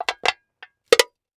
Metal Can Small Crushing Sound
household